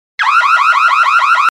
/64kbps) Описание: Автосигнализация ID 177764 Просмотрен 450 раз Скачан 39 раз Скопируй ссылку и скачай Fget-ом в течение 1-2 дней!
avto_signal.mp3